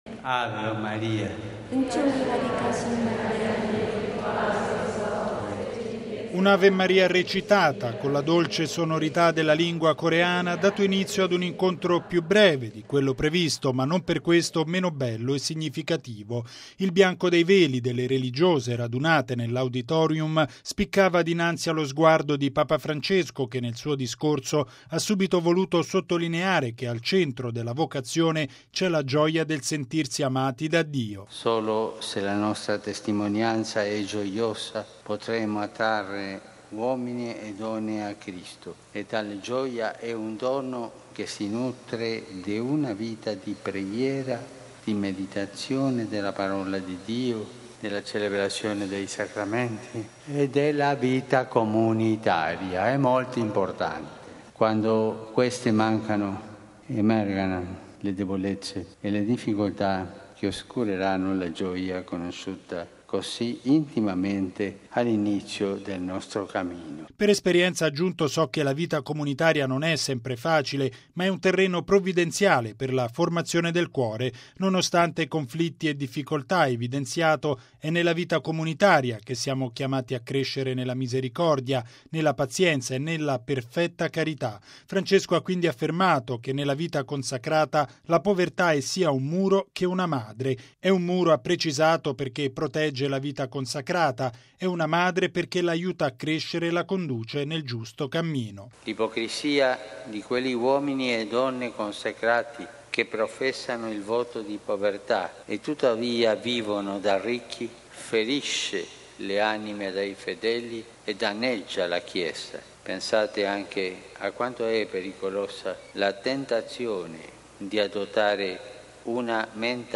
E’ quanto sottolineato da Papa Francesco nell’incontro con le comunità religiose coreane, nel grande auditorium della “School of Love” di Kkottongnae. Il Papa ha avvertito che non si può fare voto di povertà e poi vivere da ricco, è un’ipocrisia che danneggia la Chiesa.
Un’Ave Maria recitata con la dolce sonorità della lingua coreana ha dato inizio ad un incontro più breve di quello previsto ma non per questo meno bello e significativo.